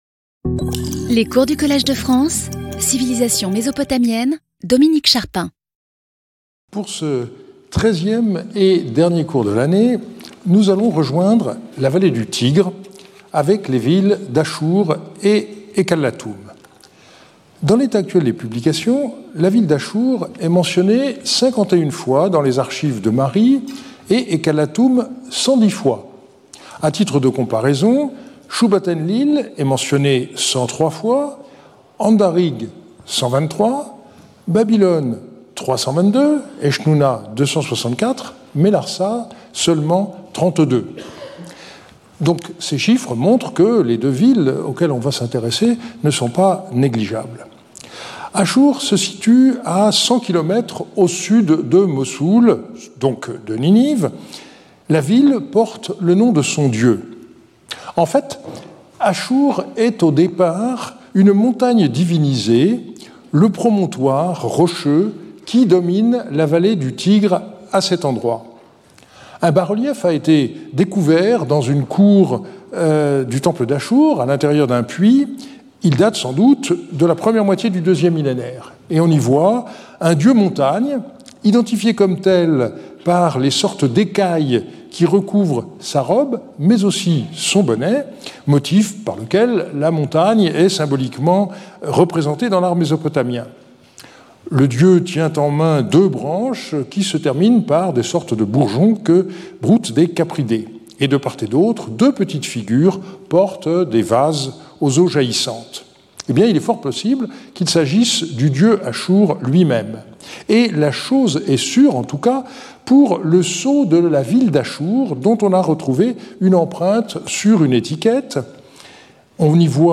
Skip youtube video player Listen to audio Speaker(s) Dominique Charpin Professor at the Collège de France Events Previous Lecture 8 Dec 2025 11:00 to 12:00 Dominique Charpin Introduction.